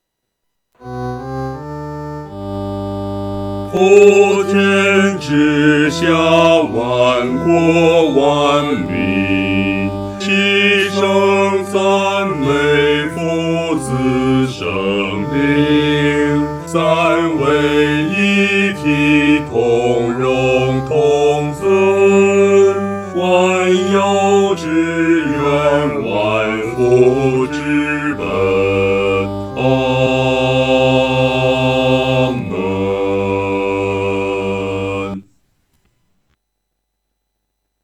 合唱
男低